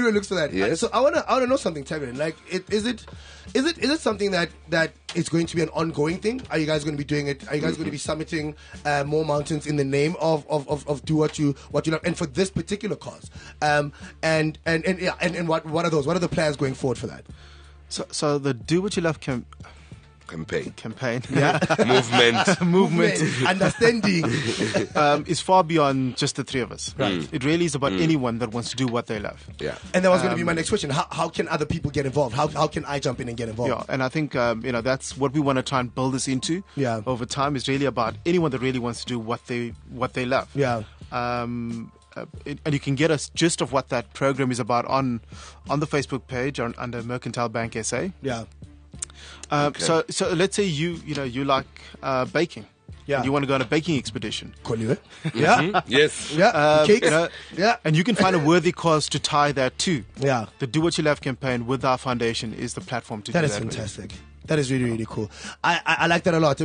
Radio interview.